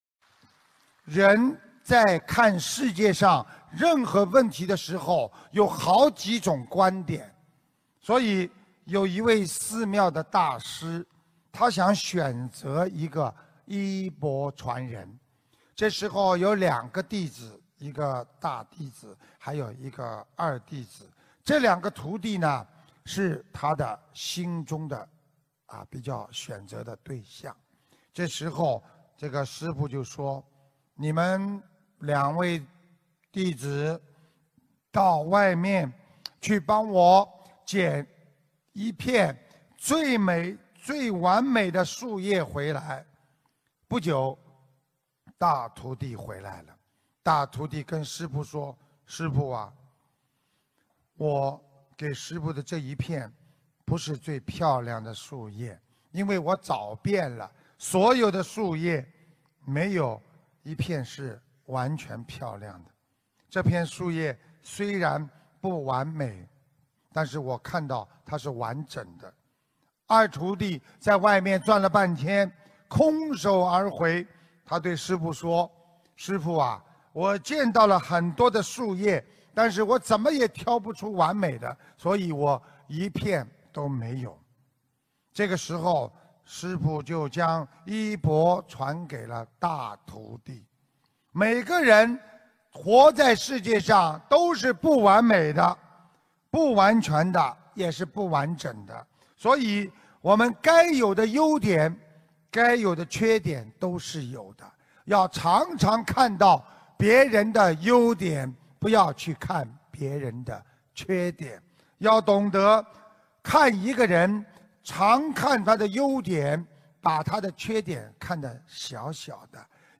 音频：每个人在这个世界上是不完美的！要学会接纳和包容别人的缺点！新加坡法会开示2014年3月8日！